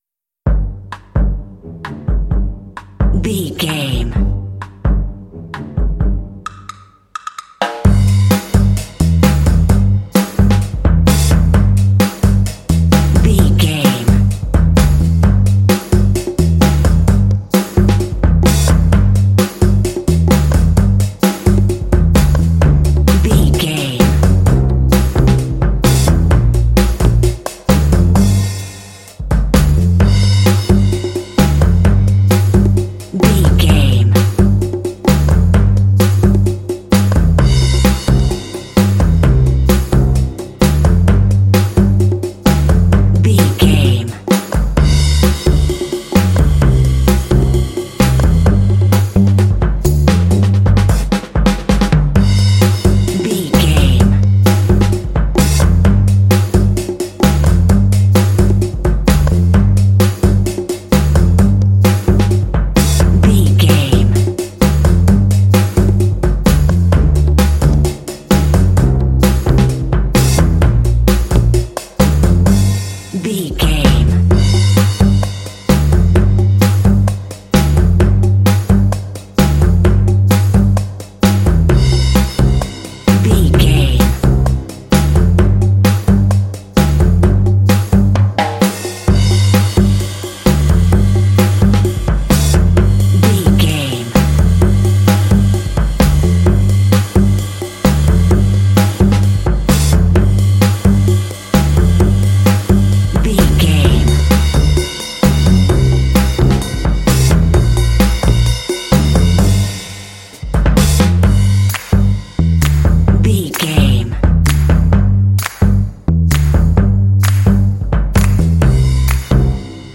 Uplifting
Aeolian/Minor
funky
smooth
groovy
driving
happy
bright
drums
brass
electric guitar
bass guitar
organ
percussion
conga
rock
Funk